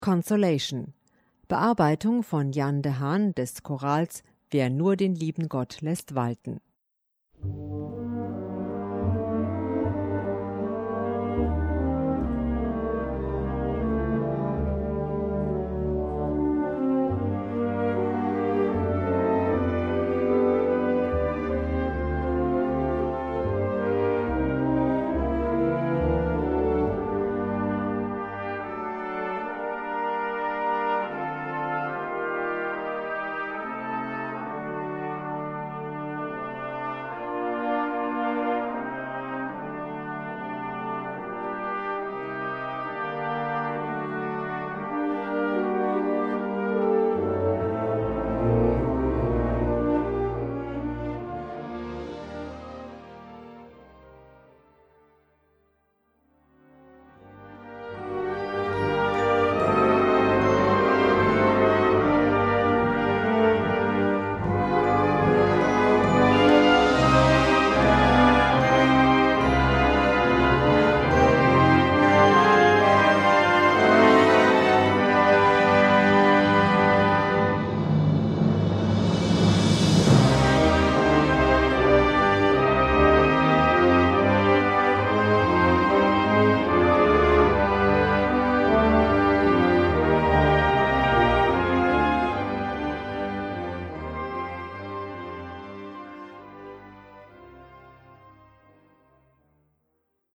Gattung: Feierliche Musik
Besetzung: Blasorchester